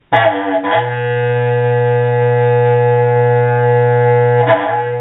berrante Meme Sound Effect
berrante.mp3